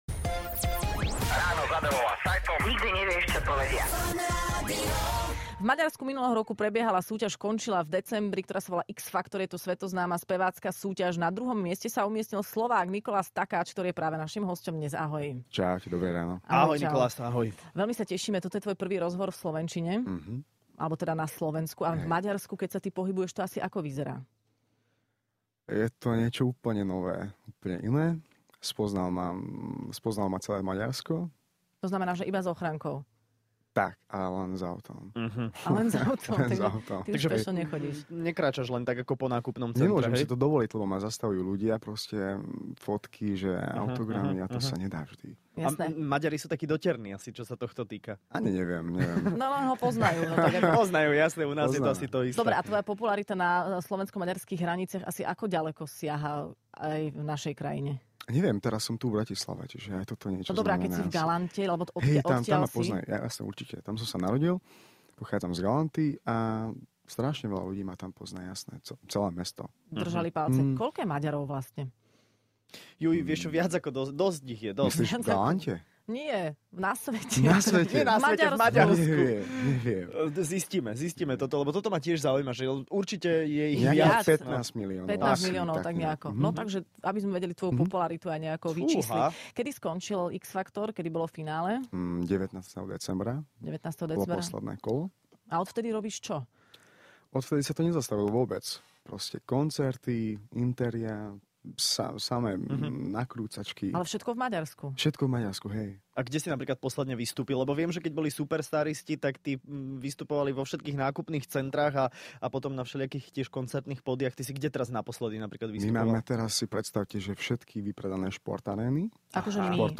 Hosť